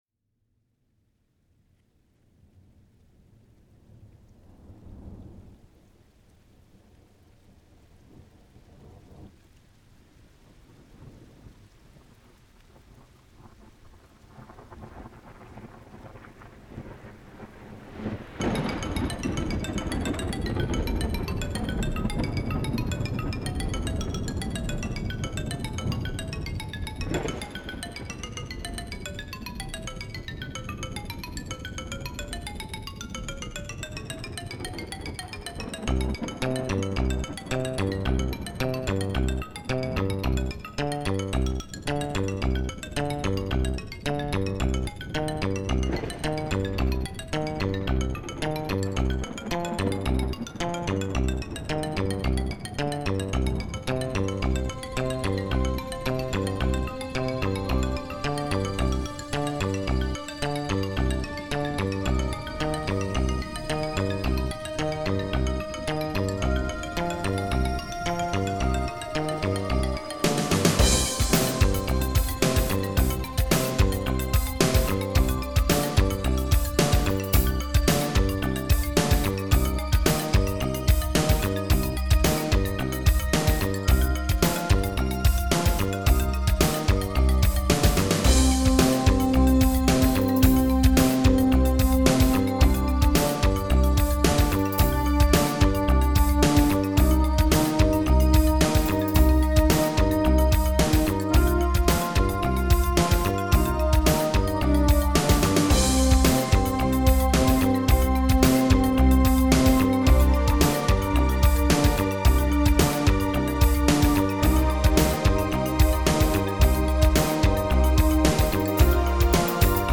Electronique / New age
Roland D 110, Roland S 550, Roland SPD 20, Roland XP 10, Eminent Solina String Ensemble, Roland JD 800, Clavia Nord Modular, Guitare électrique Fender Stratocaster avec Livepod Xt live, Guitare basse Fender Jazz Bass avec Boss GT 10B.